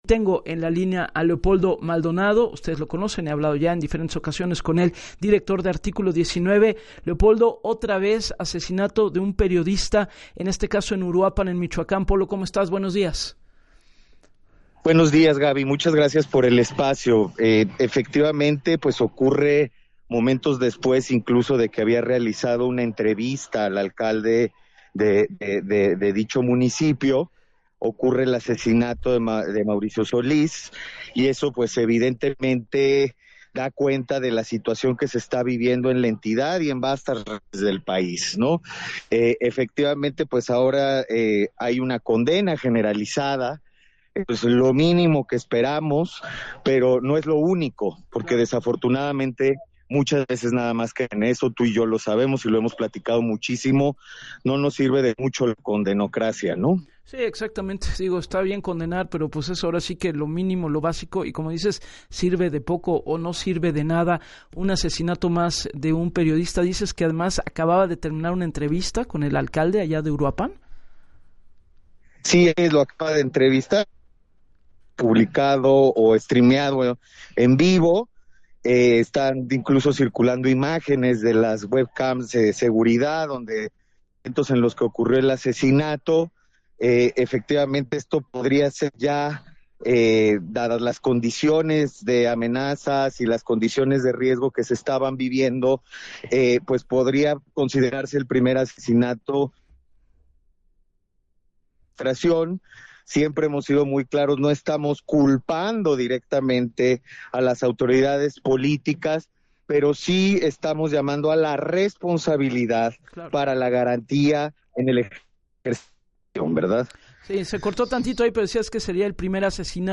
En entrevista para “Así las Cosas” con Gabriela Warkentin, puntualizó “no estamos culpando directamente a las autoridades políticas, pero sí llamando a la responsabilidad para la garantía en el ejercicio de su labor”.